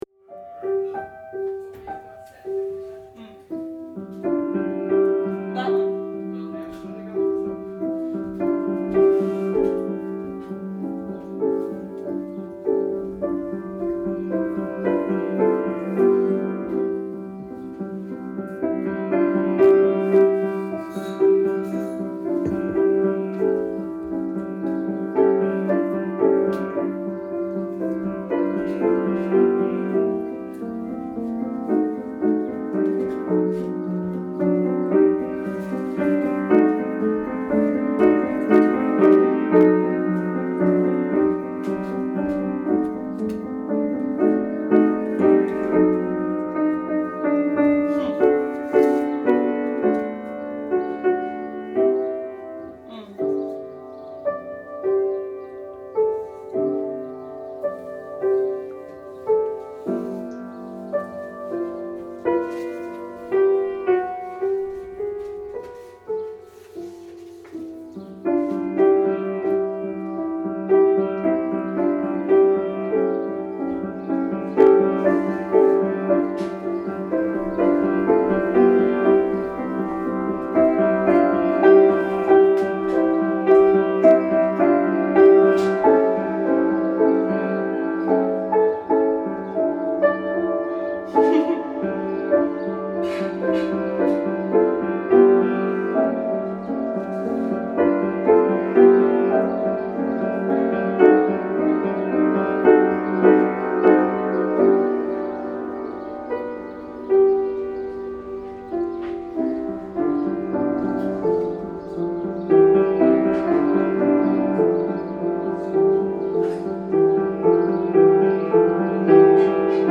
came back, and played the piano